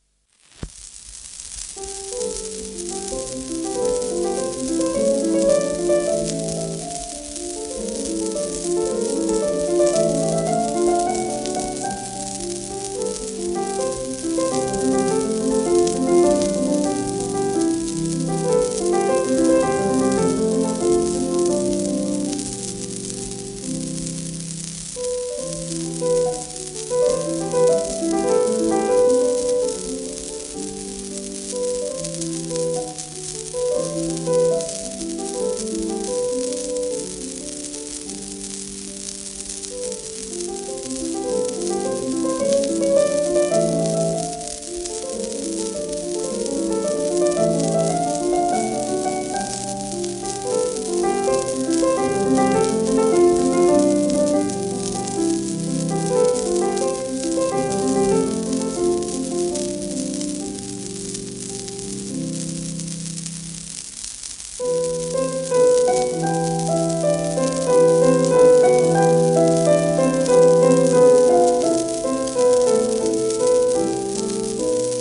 1947年録音